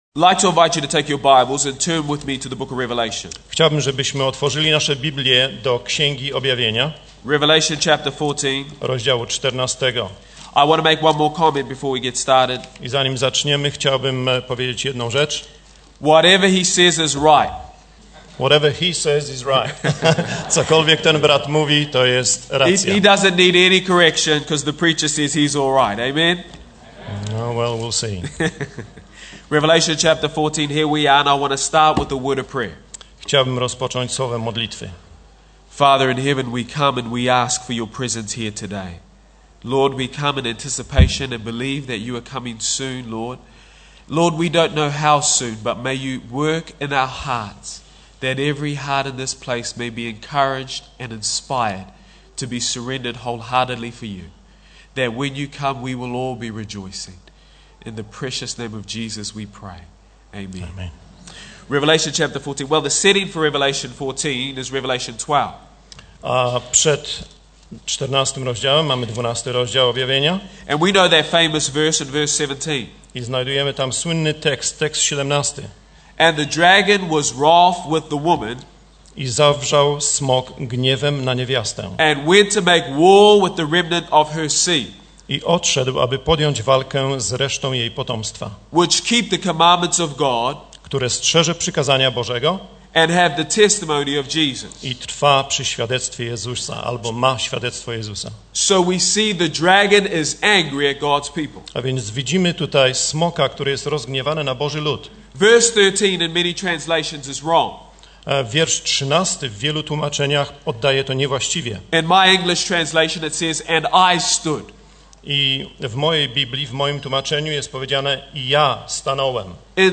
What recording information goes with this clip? inauguracja XVII Kongresu, Polski Kościół Adwentystów Dnia Siódmego w Dandenong, Melbourne, Australia inauguration of XVII Congress, Dandenong Polish Seventh-day Adventist Church, Melbourne, Australia